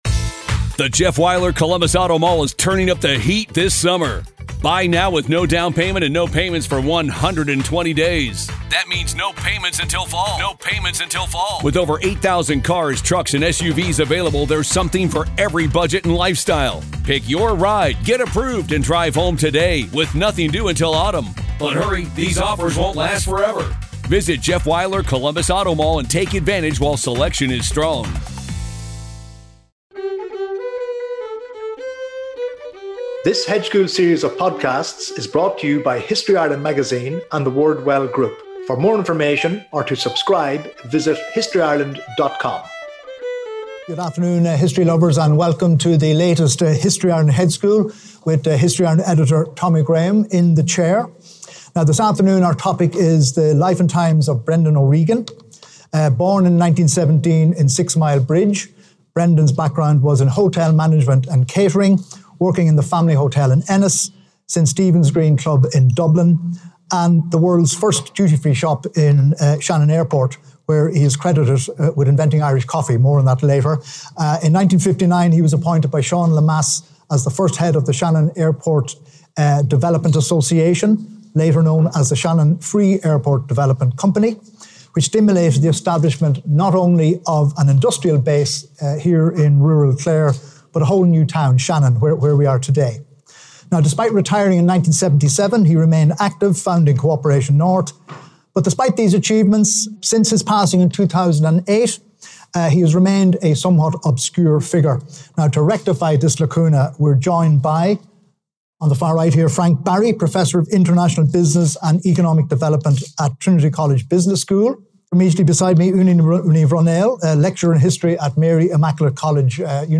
(Recorded live on Sat 13 April ’24, @ Shannon College of Hotel Management, Co. Clare) This Hedge School is supported by Sixmilebridge Historical Society.